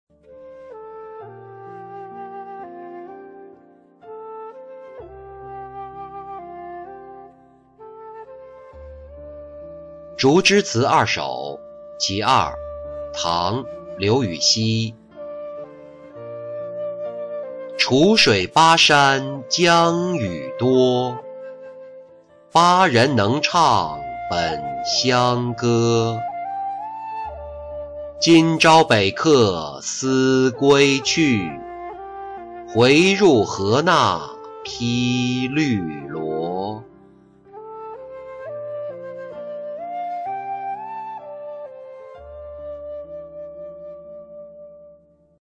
竹枝词二首·其二-音频朗读